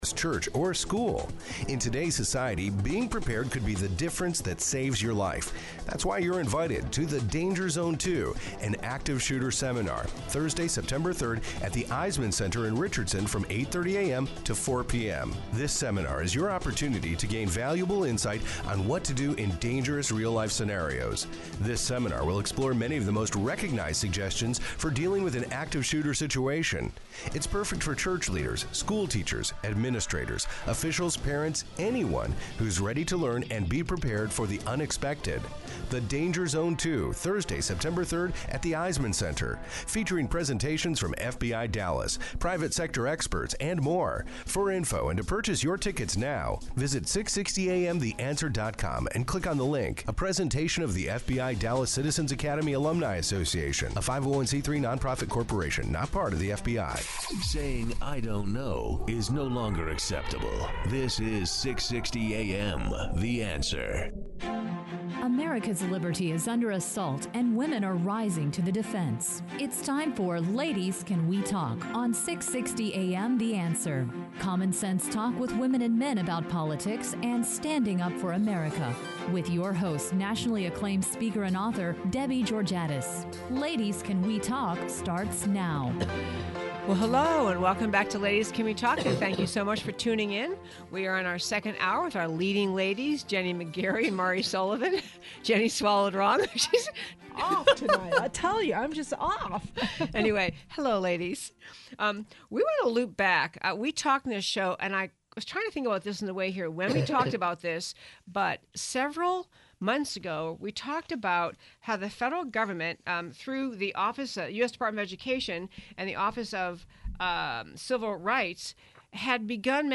Listen to the podcast from the second hour of our August 23rd show on 660AM.